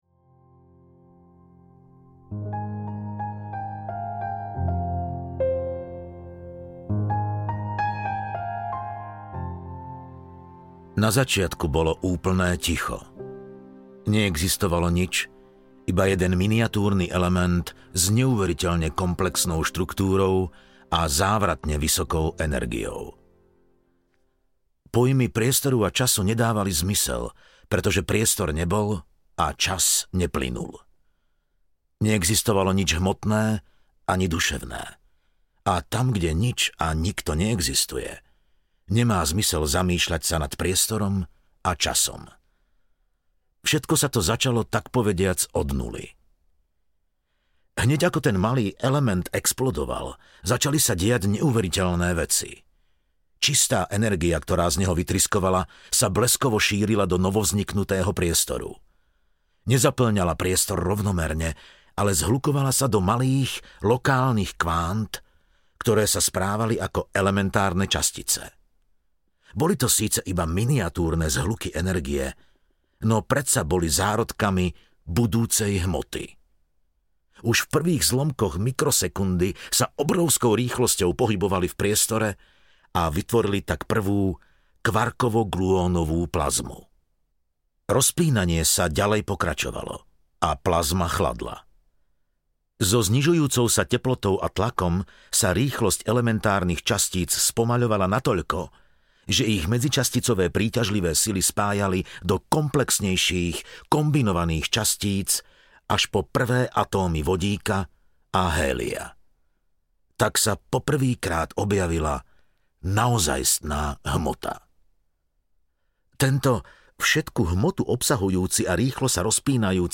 Príbeh Vesmíru audiokniha
Ukázka z knihy
• InterpretMatej Landl
pribeh-vesmiru-audiokniha